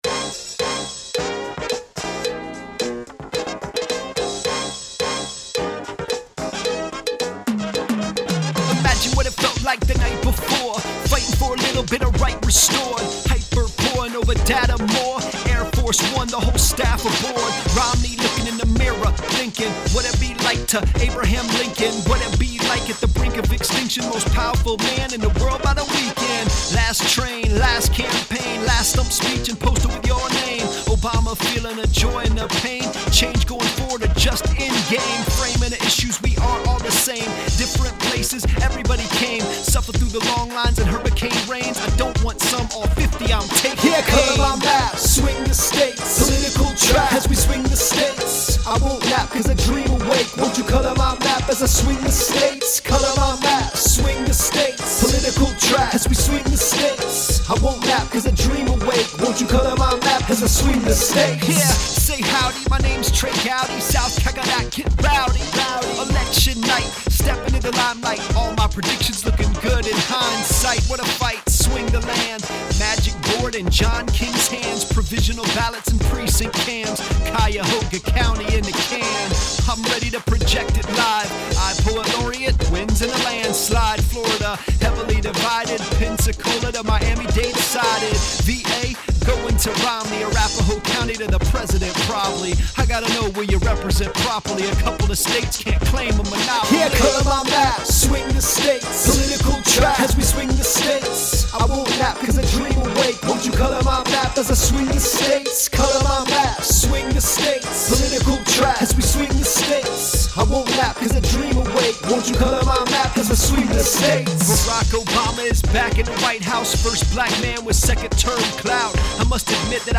a wonderful match of lyrics and the beat
As a fellow crafter of hip hop music, my biggest struggle is finding production that has the right sound and feel for the point I’m trying to lyrically get across.
But, I didn’t know that vocal sample at the end the beat was on their until the song was done.